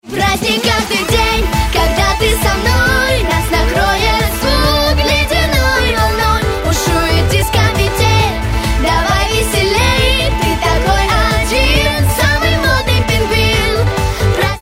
Помогите подобрать звучание арпеджио
В примере курлыкает в правом ухе.
Сори, я рукожоп, сам крутить ручки не умею ((( Если что, это Ля минор. 135 bpm :)